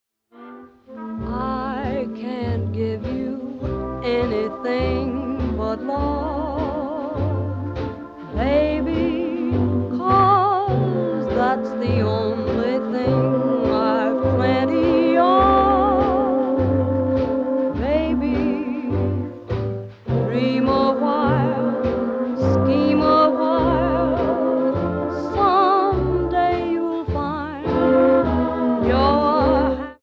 Original recordings from 1931 - 1941, they're all winners.